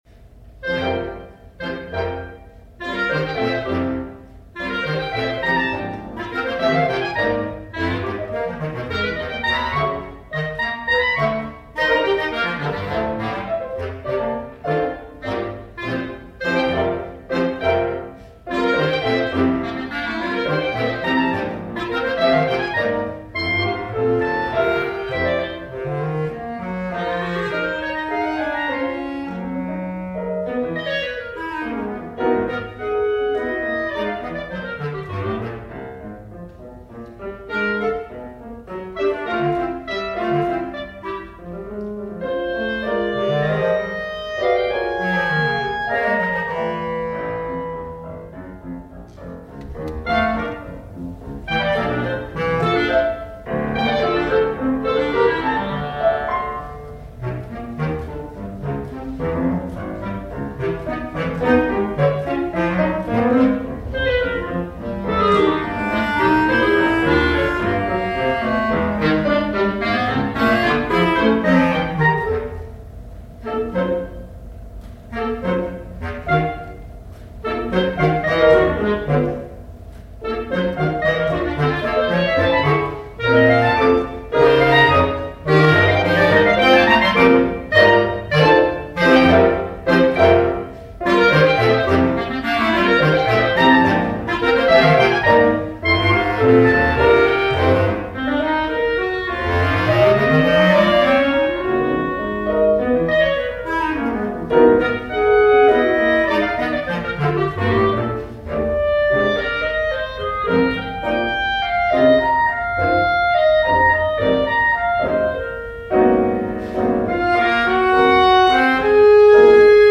Voicing: Clarinet Duet